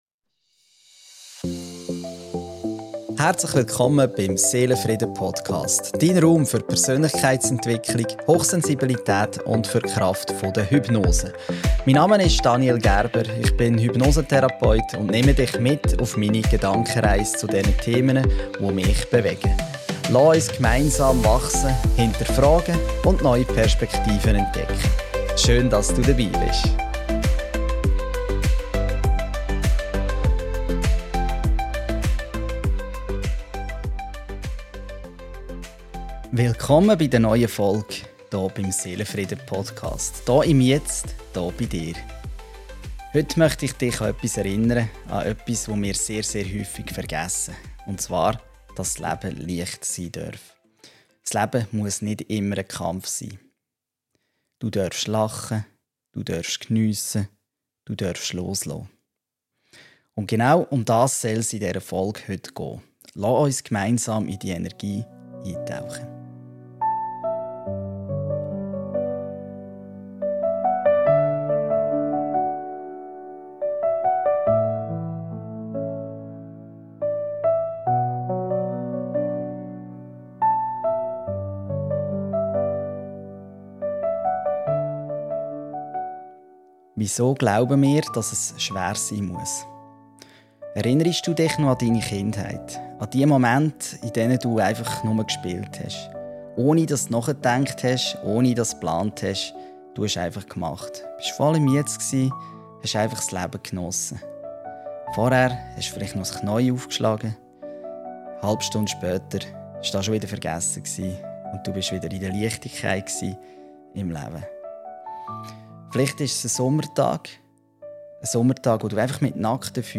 Mit sanfter Musik, emotionalen Geschichten und tiefgehenden Impulsen nehmen wir uns gemeinsam Zeit für mehr Vertrauen, mehr Liebe und mehr Verbundenheit.